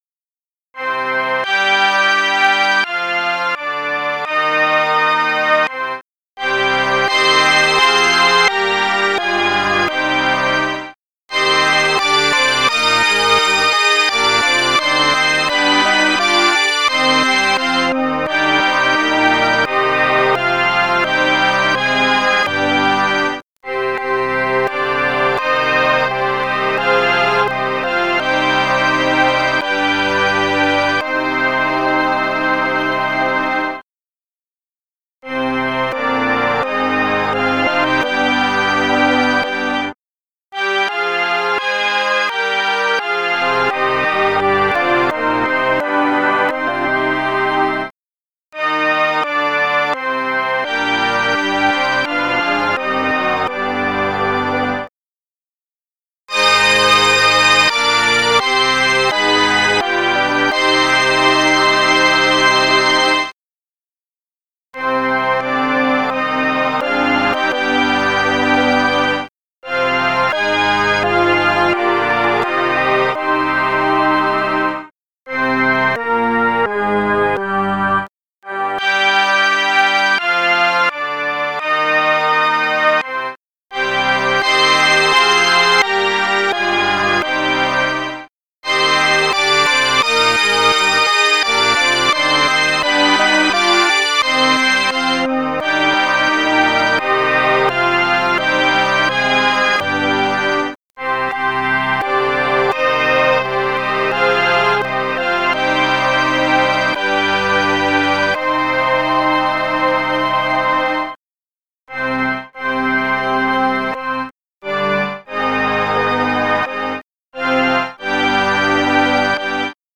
Chants byzantins arabes :
anaphore 5e ton PA nahawand 1 RE mineur (3 V)